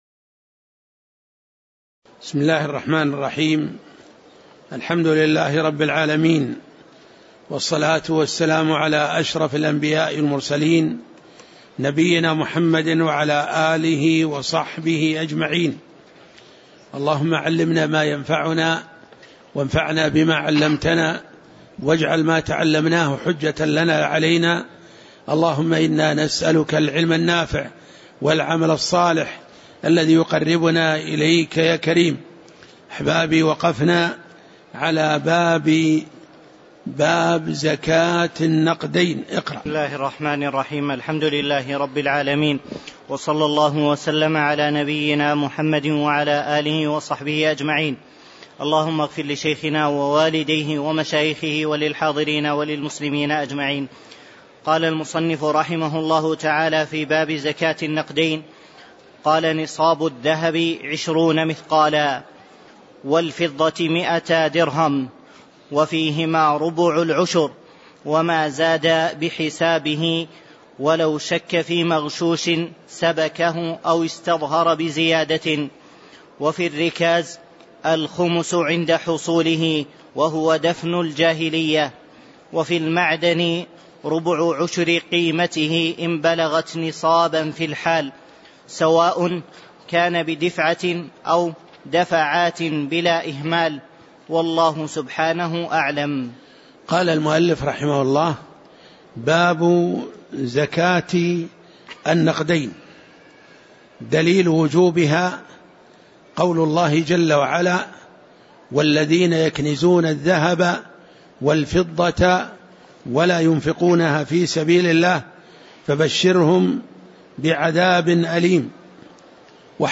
تاريخ النشر ١٨ شوال ١٤٣٩ هـ المكان: المسجد النبوي الشيخ